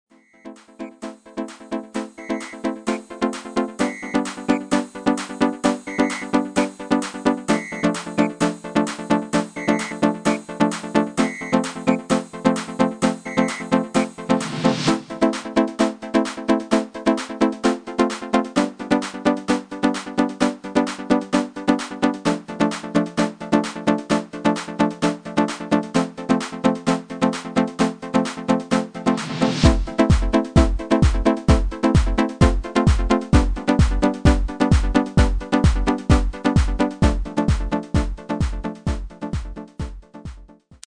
Demo/Koop midifile
Genre: Dance / Techno / HipHop / Jump
- Vocal harmony tracks
Demo = Demo midifile